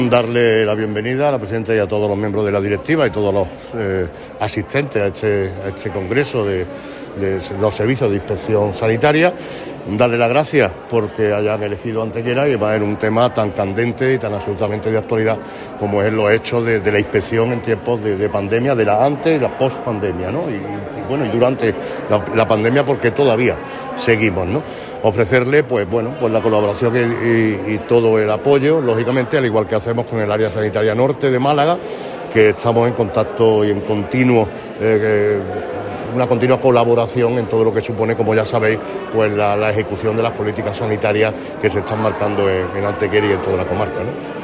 El Alcalde Manolo Barón inaugura la XIV Jornada Técnica de Inspección de Servicios Sanitarios que se desarrolla hoy en nuestra ciudad
Cortes de voz M. Barón 347.81 kb Formato: mp3